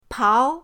pao2.mp3